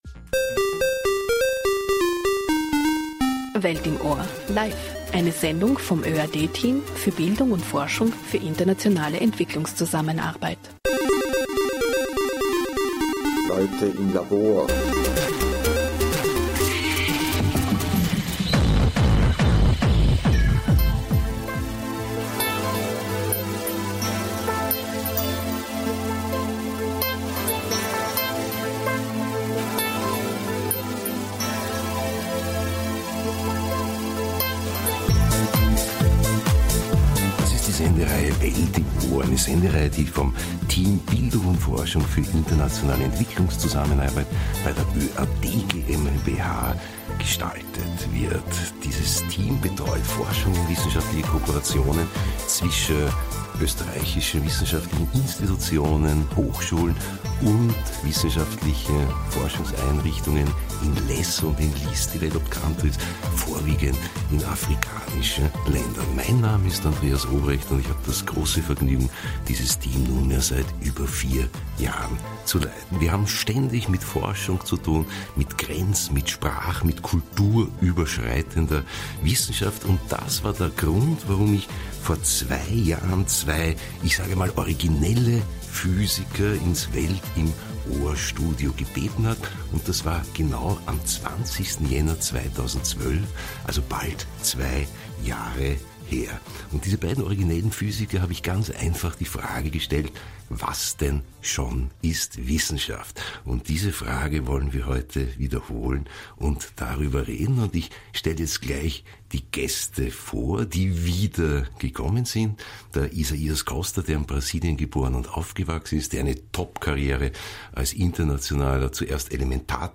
Ein Gespräch über die Grundlagen des wissenschaftlichen Denkens